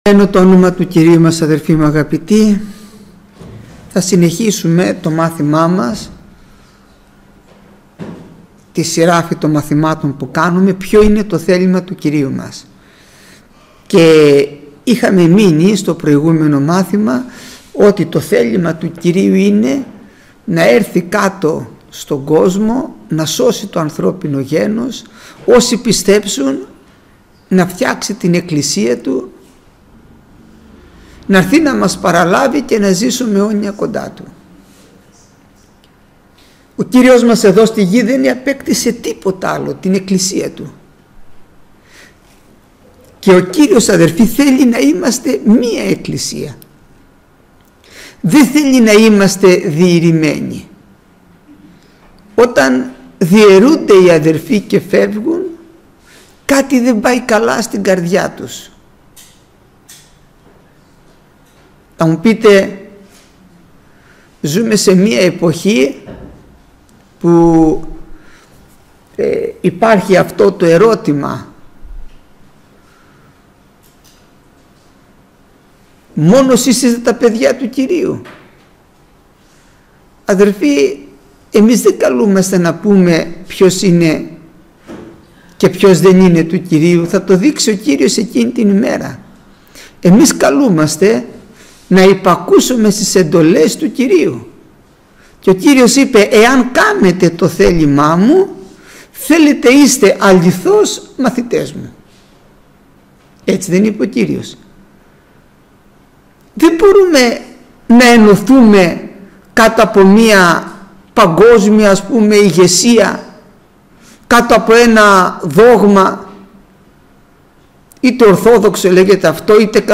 Γεννηθήτω το θέλημά σου (η εκκλησία) – Μάθημα 77ο